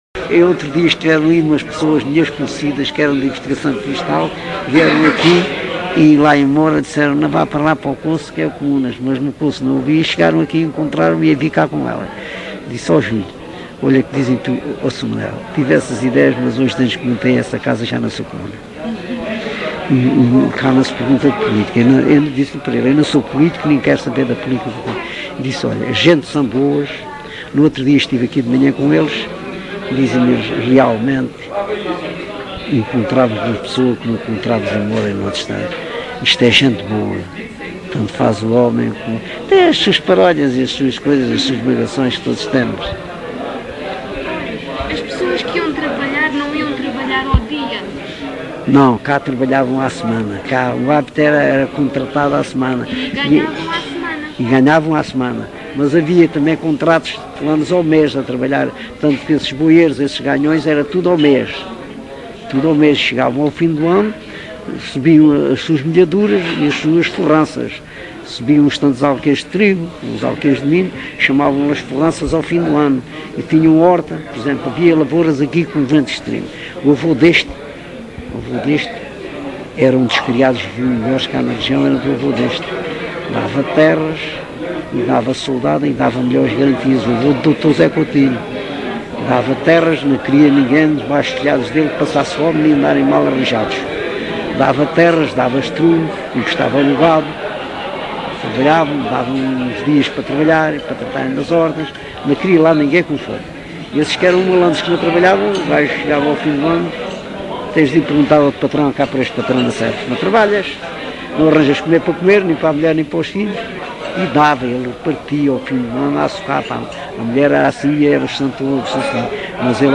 LocalidadeCouço (Coruche, Santarém)